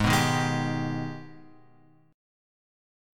G# Minor Major 7th